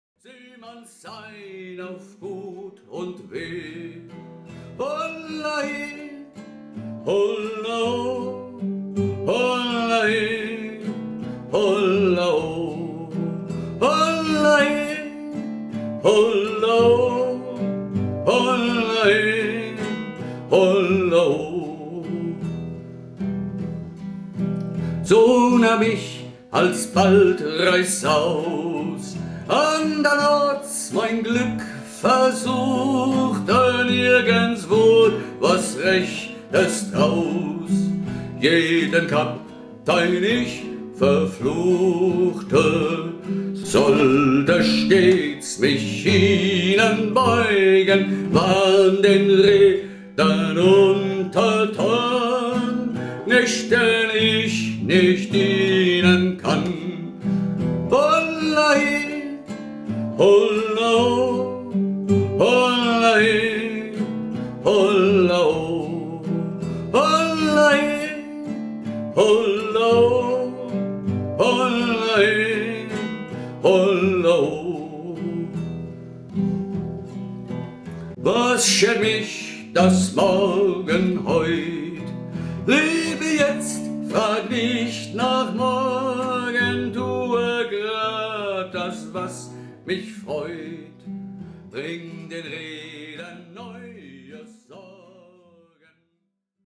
Gruppenfassung